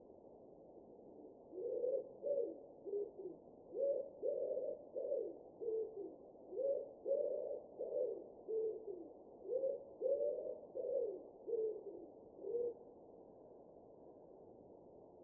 Columba palumbus
Canto
O voo da Trocaza é rápido e poderoso, acompañado dun característico son de ás que é fácil de identificar, ademais do seu arrullo distintivo, que consiste nunha serie de arrulos profundos e resonantes.